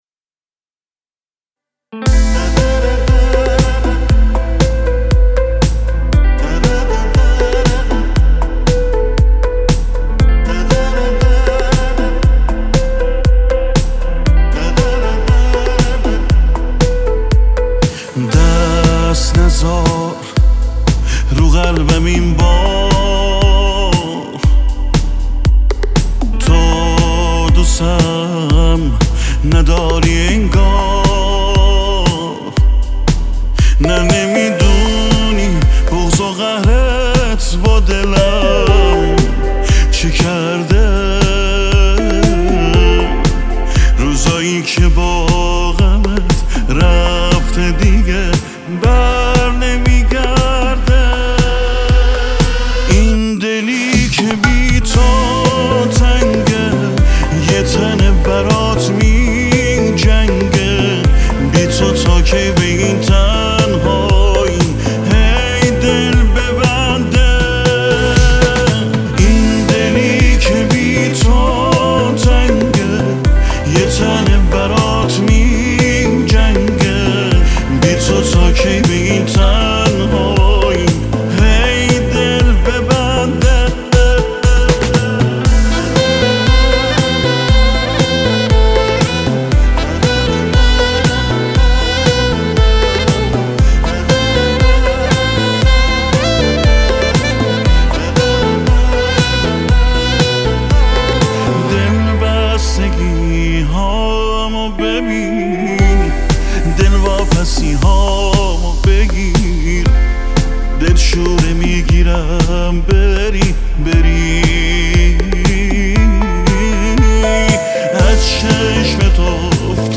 موسیقی ایرانی